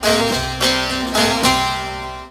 SAZ 04.AIF.wav